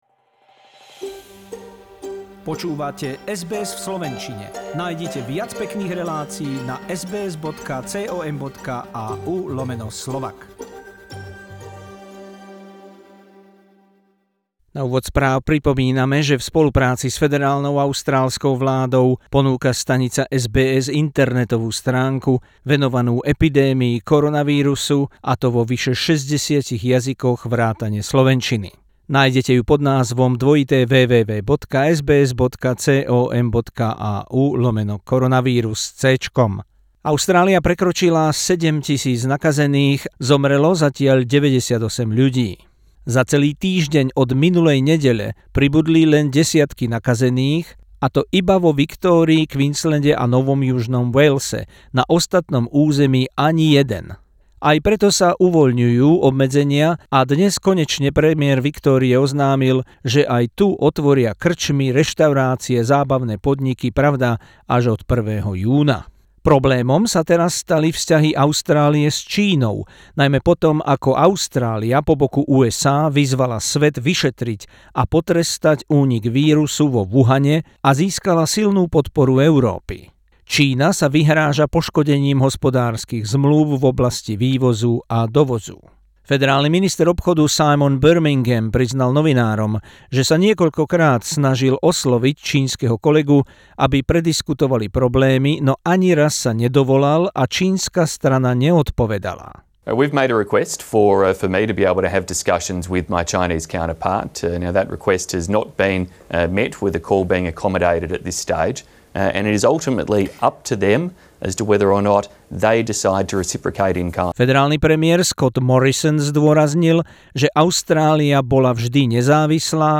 News bulletin in Slovak language on SBS Radio Australia from Sunday 17th May 2020, including extensive coverage of COVID-19.